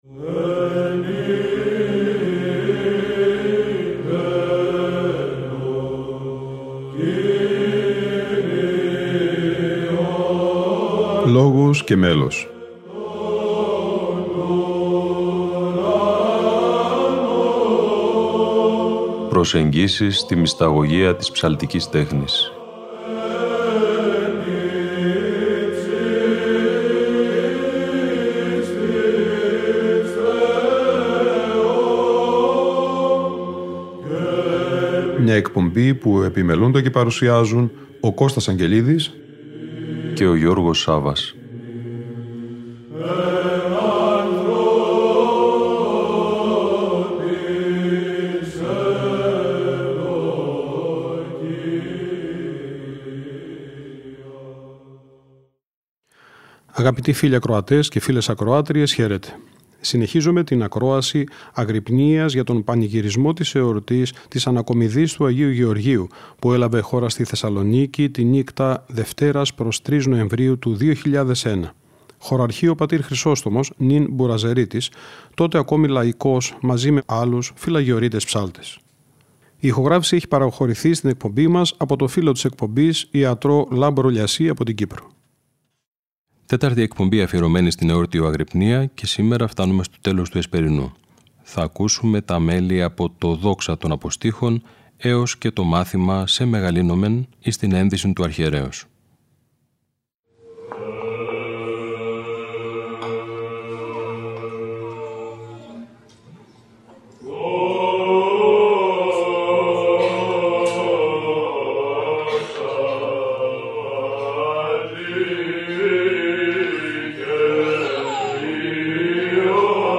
Αγρυπνία Ανακομιδής Λειψάνων Αγίου Γεωργίου - Ροτόντα 2001 (Δ΄)